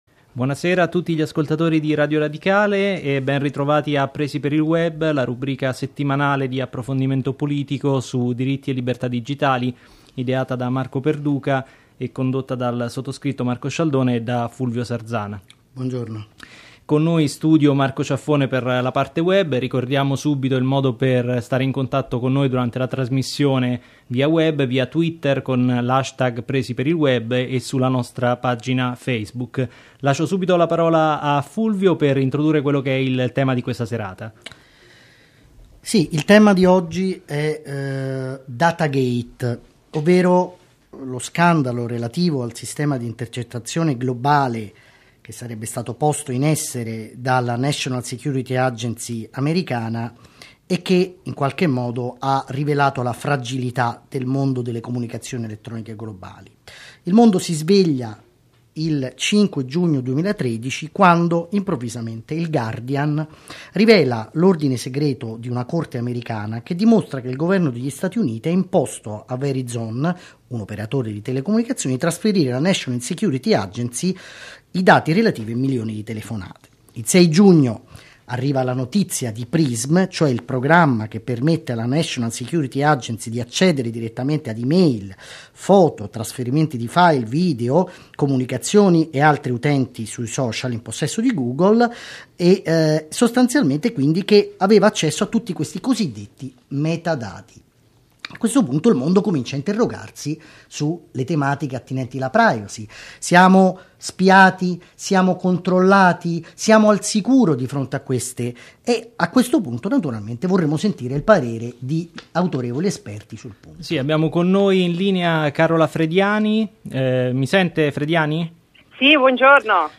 Seconda puntata di "Presi per il Web", la trasmissione radiofonica che dal 15 settembre 2013 va in onda su Radio Radicale alle 19.45 della domenica.